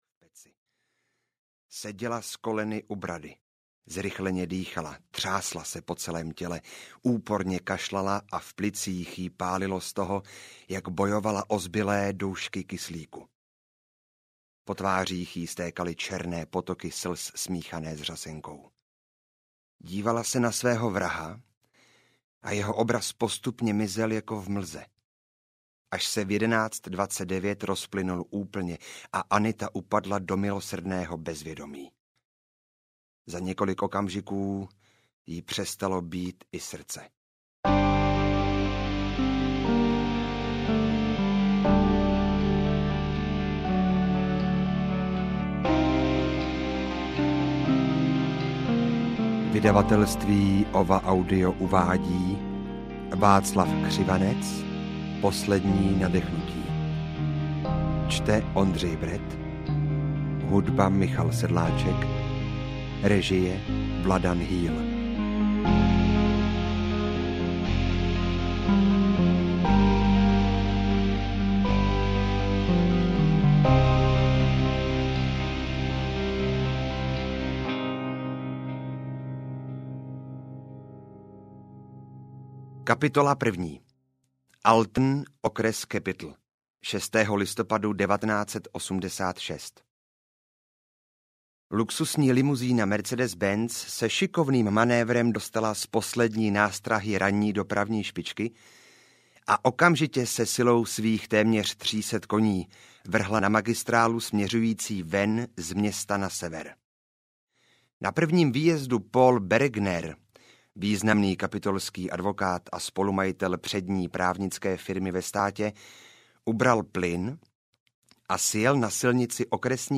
Poslední nadechnutí audiokniha
Ukázka z knihy